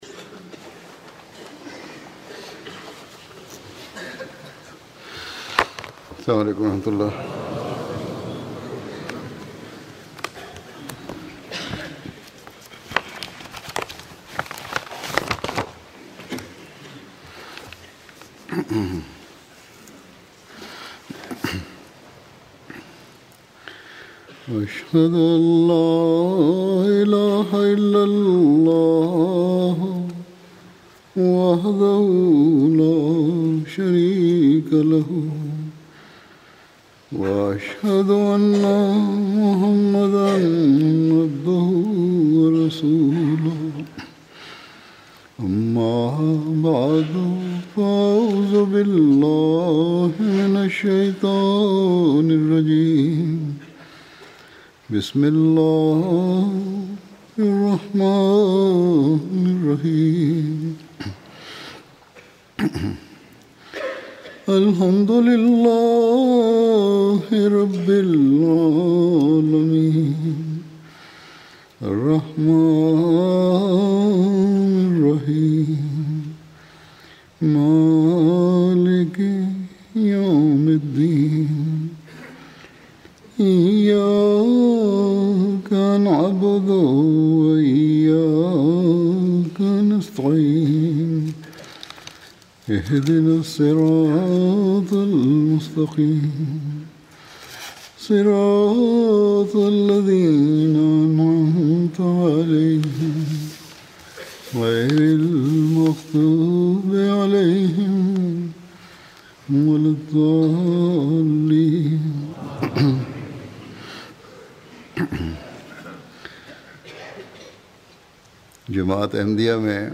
Sermon du vendredi 21 février 2020, prononcé par Sa Sainteté le Calife, Hadrat Mirza Masroor Ahmad, à la mosquée Baitul-Futuh à Londres.